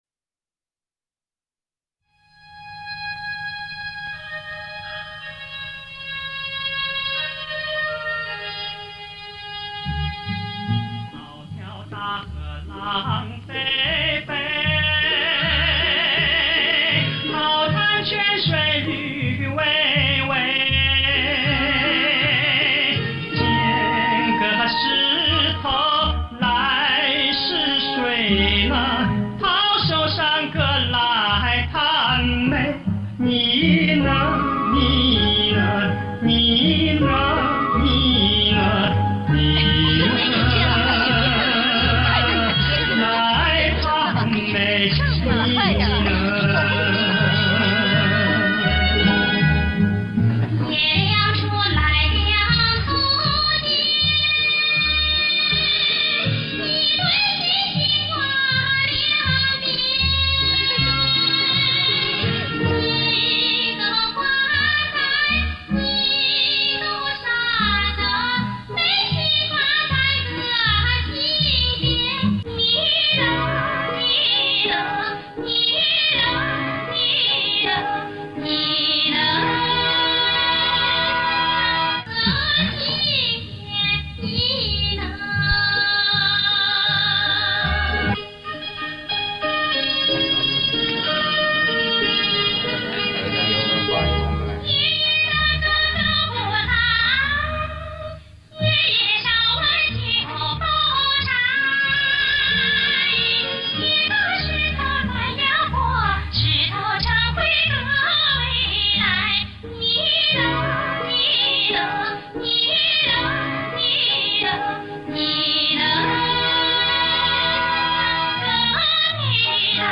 这是电影原唱。